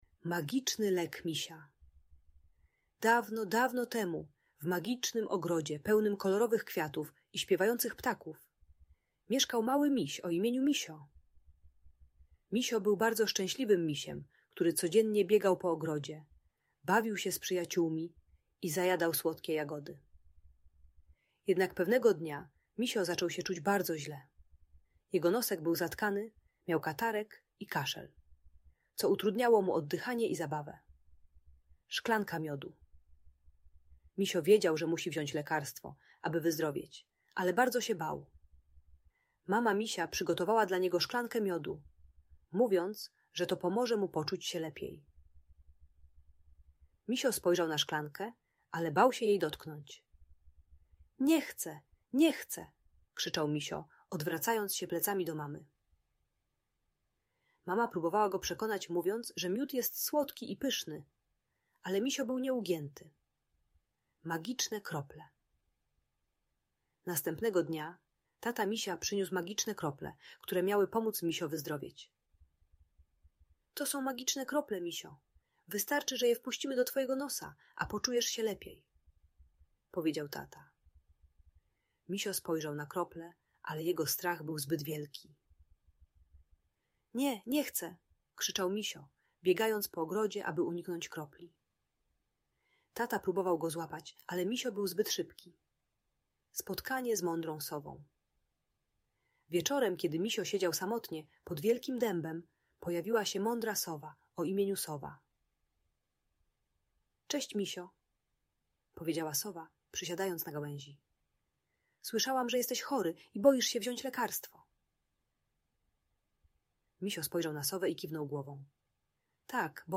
Magiczny lek Misia - Bajkowa historia o odwadze - Audiobajka